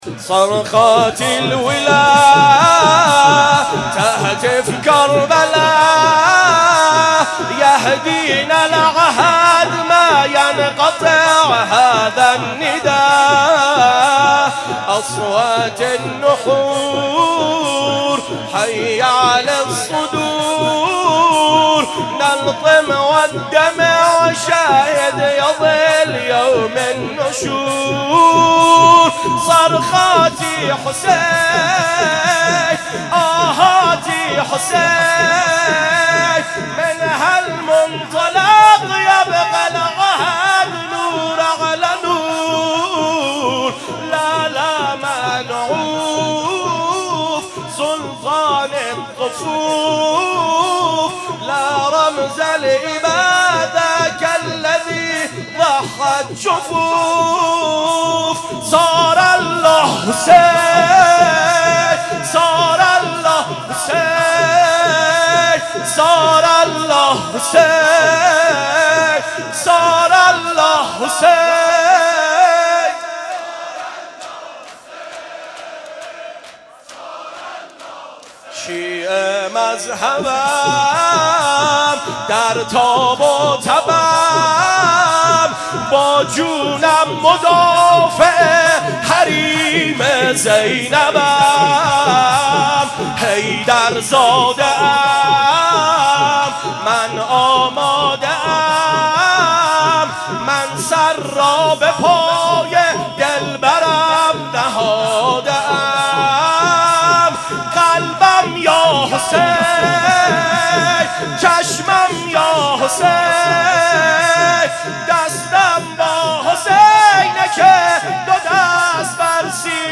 ر97 اربعین کربلا خواندم